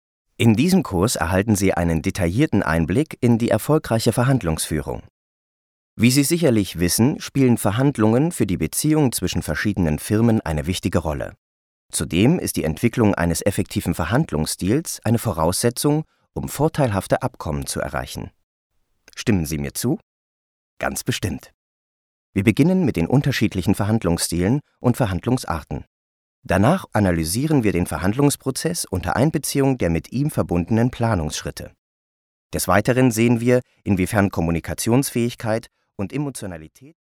Erfahrener deutscher Sprecher.
Meine Stimme kann warm, beruhigend, bestimmt, dynamisch oder energetisch klingen, so wie Sie es brauchen.
Sprechprobe: eLearning (Muttersprache):
My voice can sound warm, calming, determined, dynamic or energetic as you need it to be.